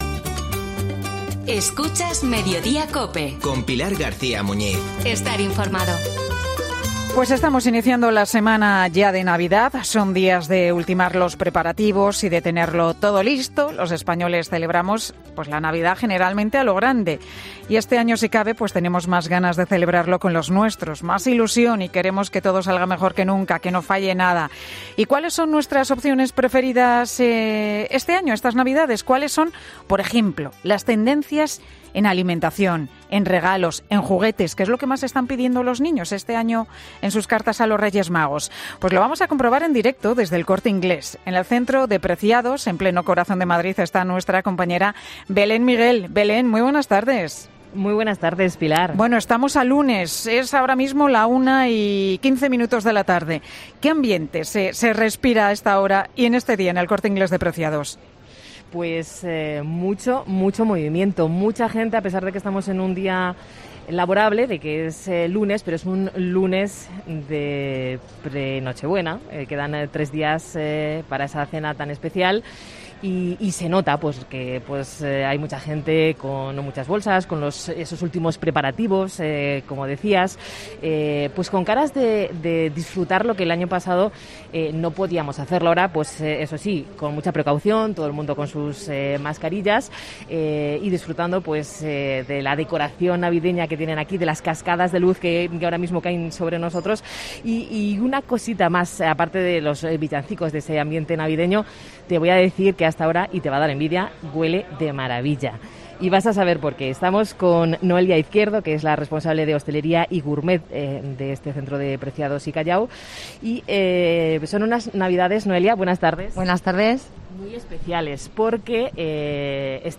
Descubre sus respuestas en directo desde El Corte Inglés.